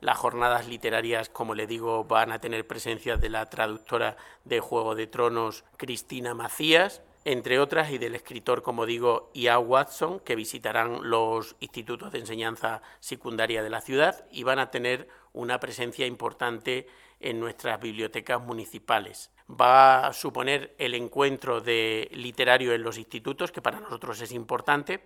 AUDIOS. Teo García, concejal de Cultura y Educación